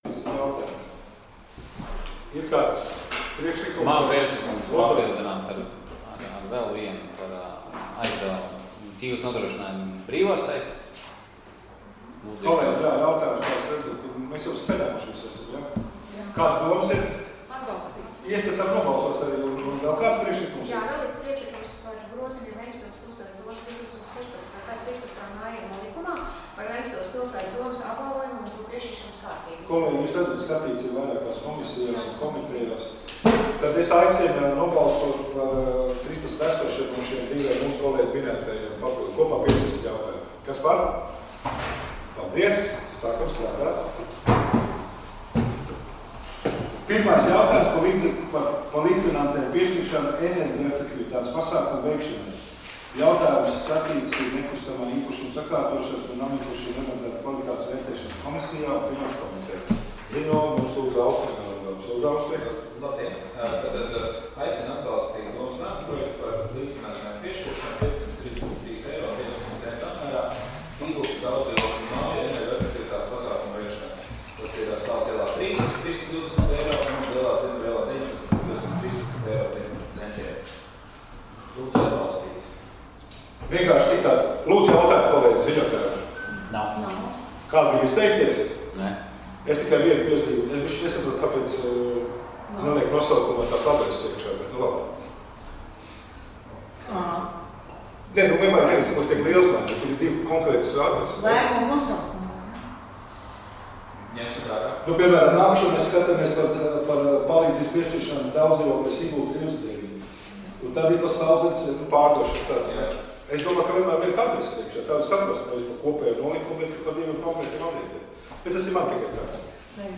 Domes sēdes 22.12.2020. audioieraksts